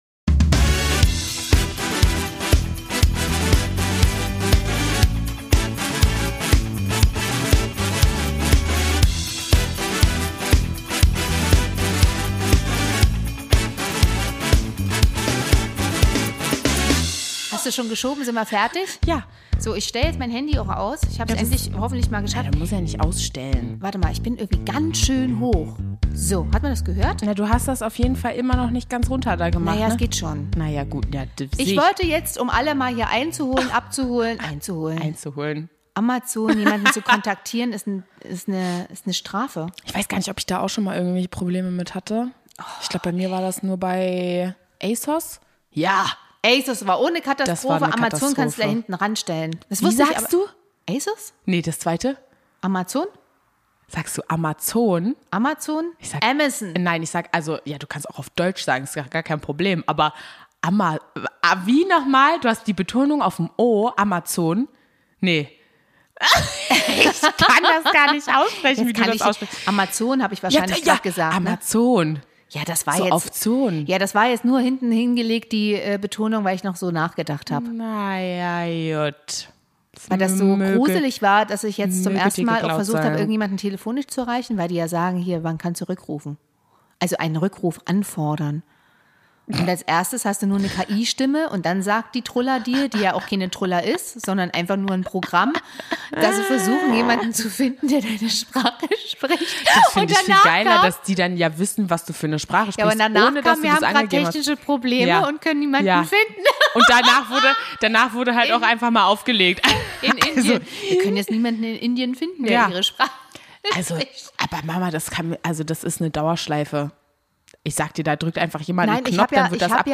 Also steht es 30:4 beim Mutter-Tochter-Podcast.